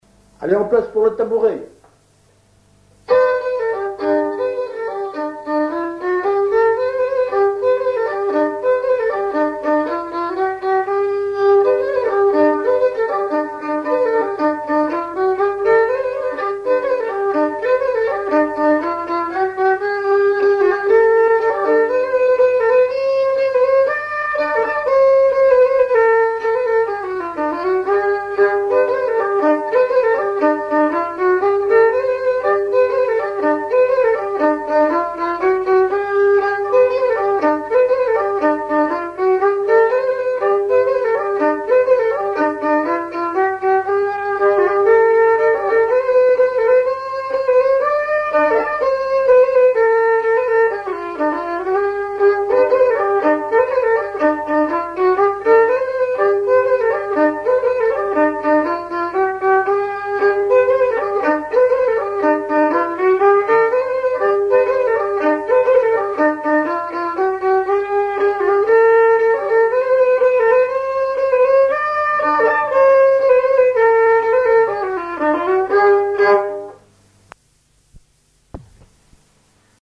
Saint-Vincent-Sterlange
Chants brefs - A danser
Auto-enregistrement
Pièce musicale inédite